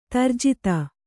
♪ tarjita